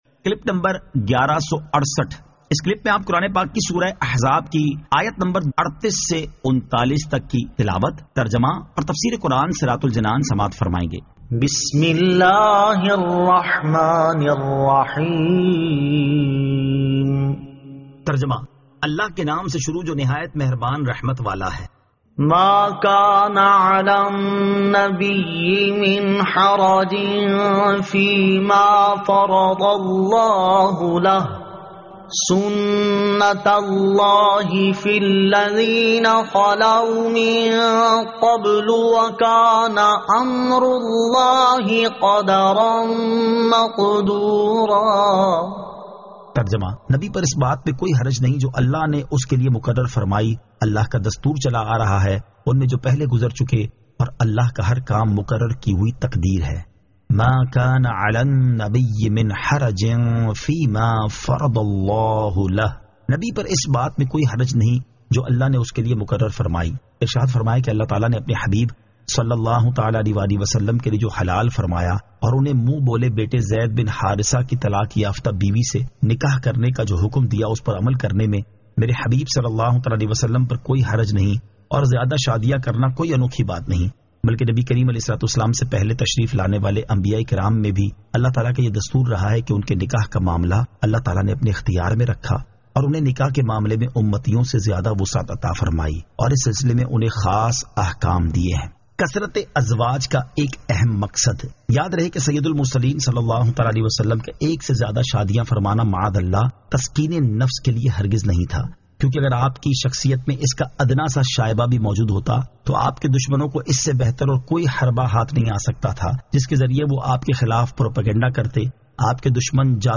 Surah Al-Ahzab 38 To 39 Tilawat , Tarjama , Tafseer